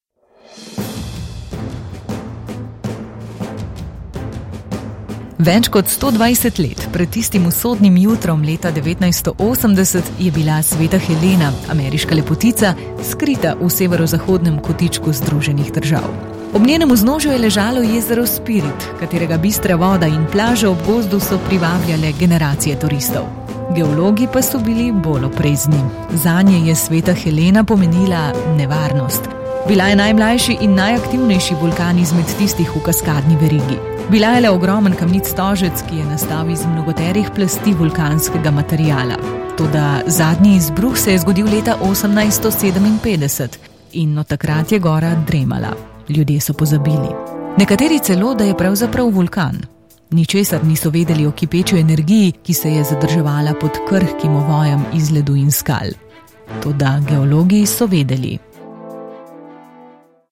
Commercial, Natural, Versatile, Reliable, Warm
Corporate